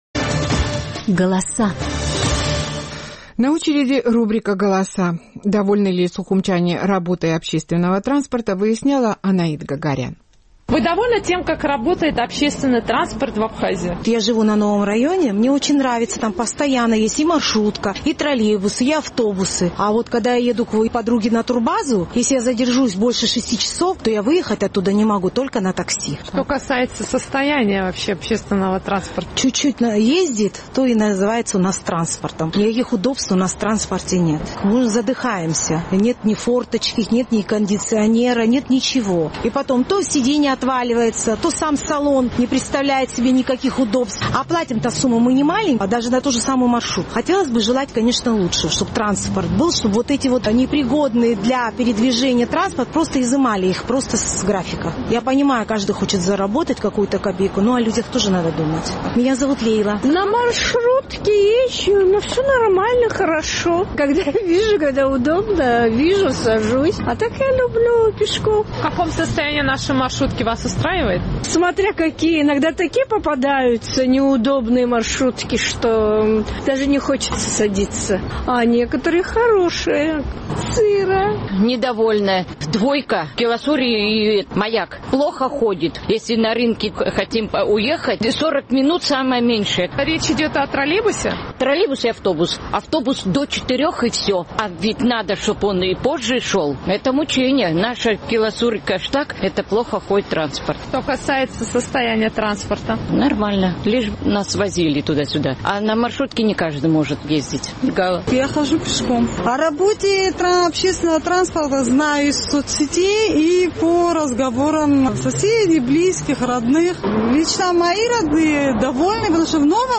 Наш сухумский корреспондент поинтересовалась у сухумчан, довольны ли они тем, как работает общественный транспорт в Абхазии.